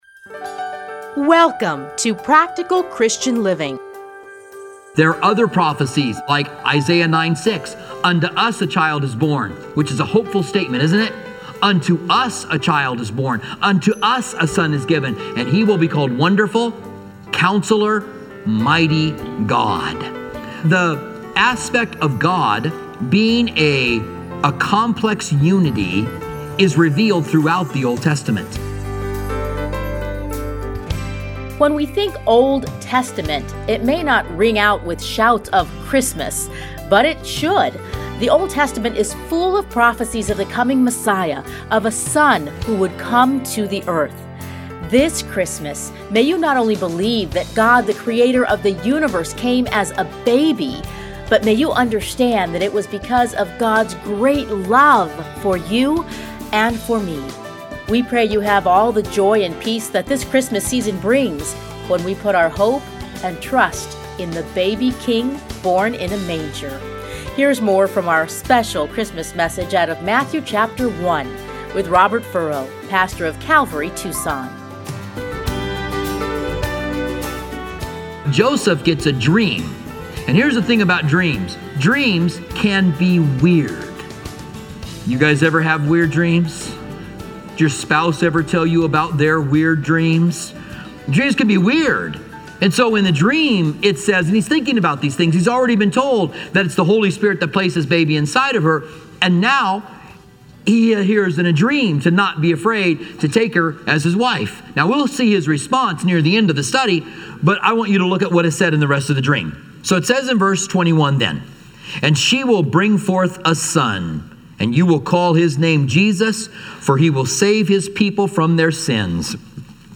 radio programs
Listen here to part 2 of his 2018 Christmas message.